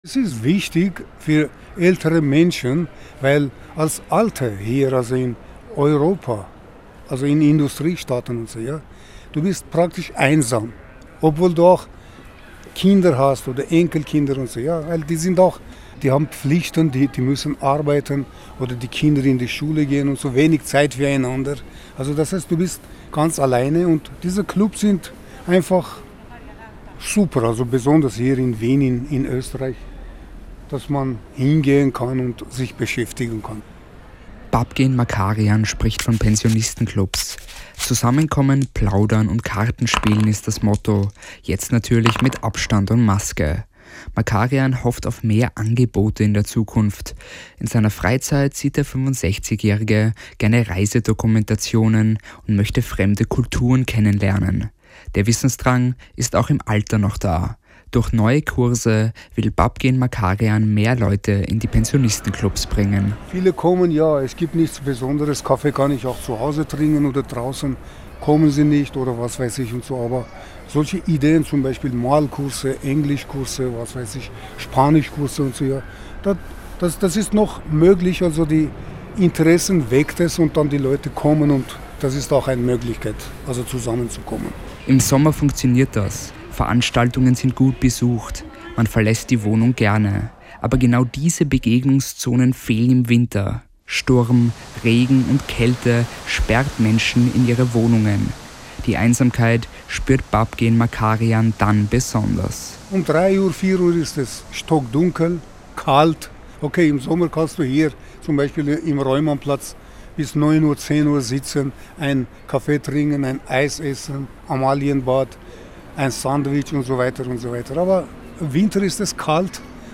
Ein Pensionist spricht offen über seine Einsamkeit und wie er damit umgeht.